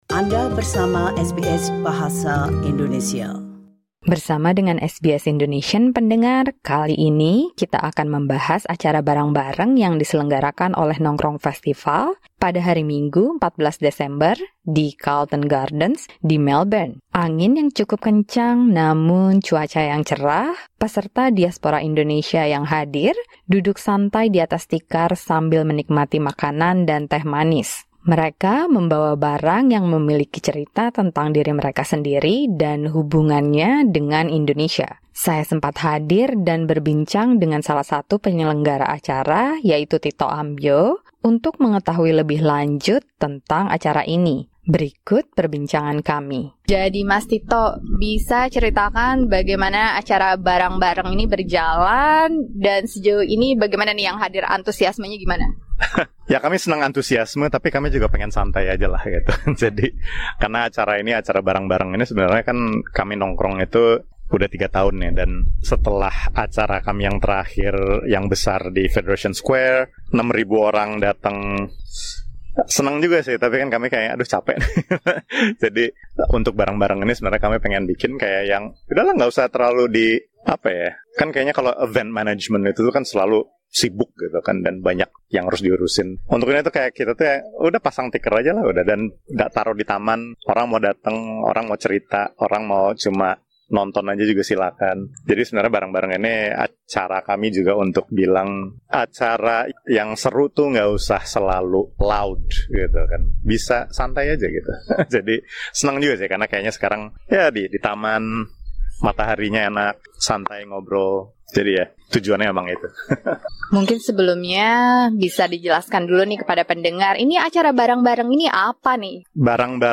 Simak perbincangan SBS Indonesian